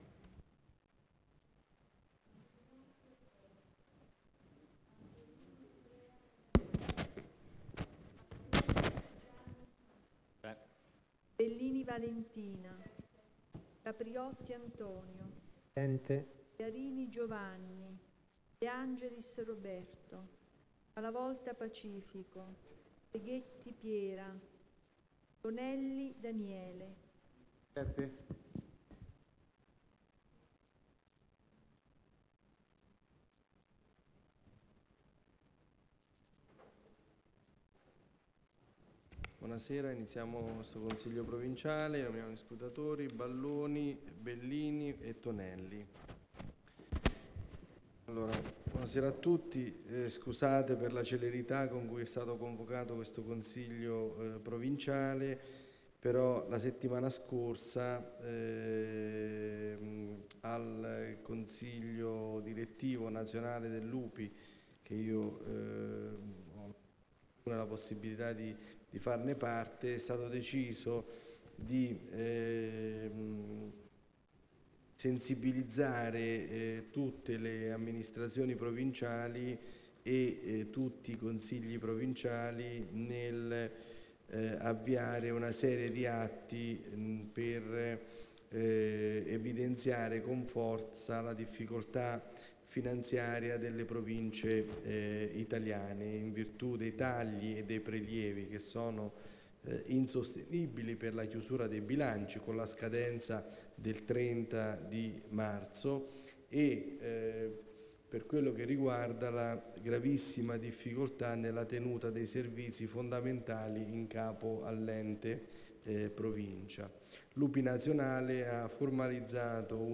Seduta del consiglio del 14 Marzo 2017